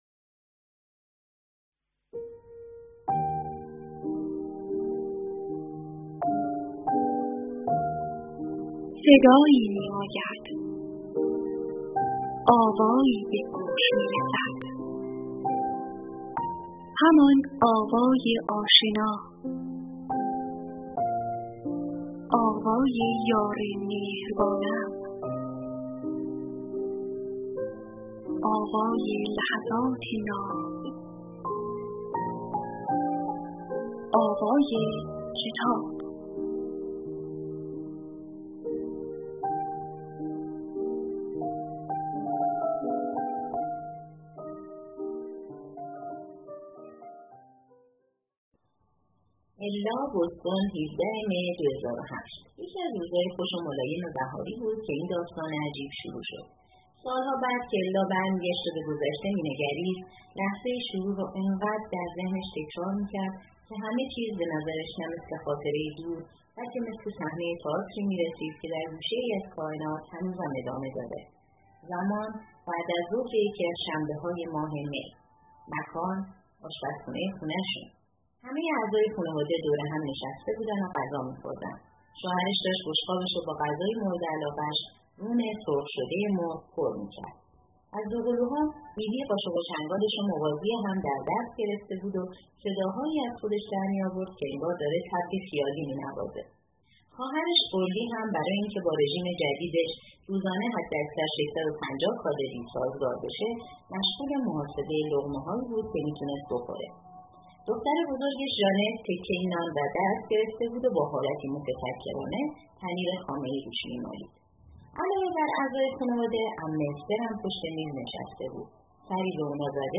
کتاب صوتی ملت عشق قسمت اول